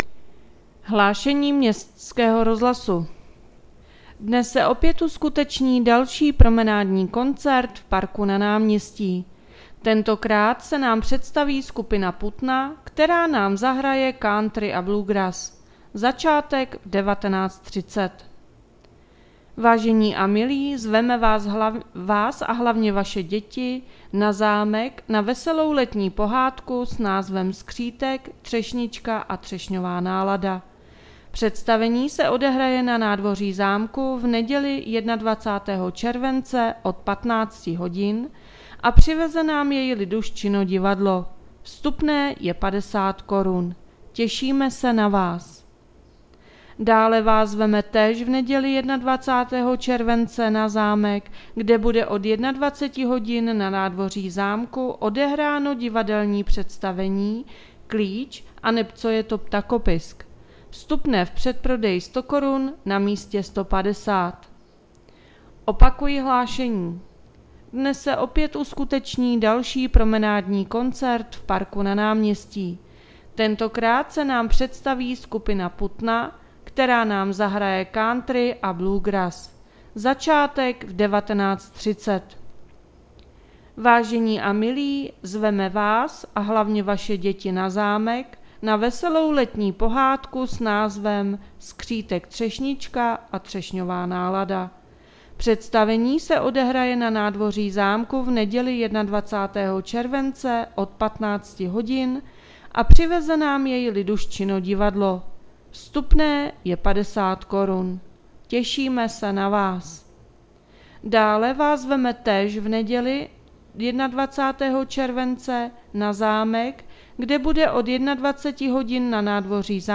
Hlášeníí městského rozhlasu 24.7.2024